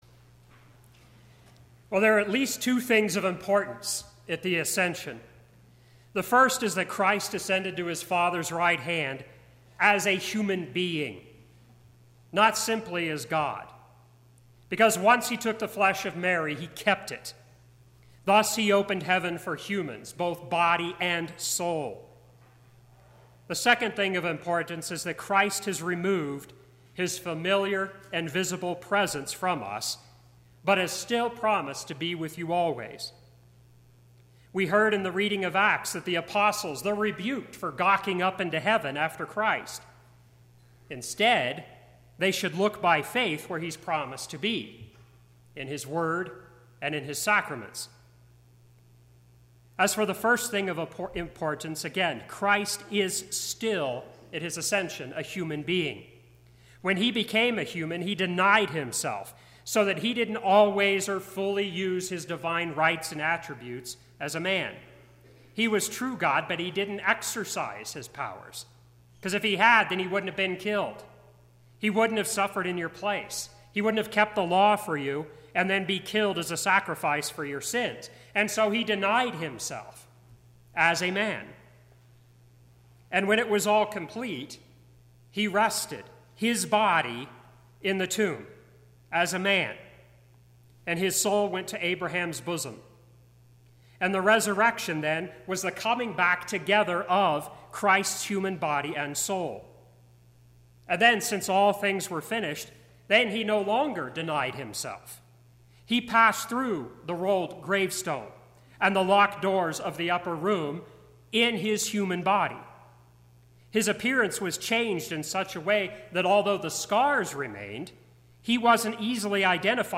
Sermon - 5/25/2017 - Wheat Ridge Lutheran Church, Wheat Ridge, Colorado